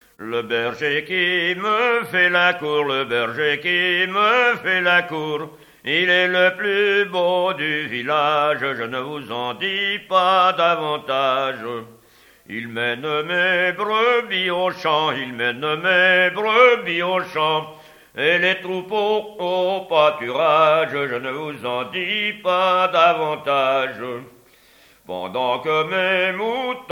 ronde : rond de l'Île d'Yeu
chansons traditionnelles
Pièce musicale inédite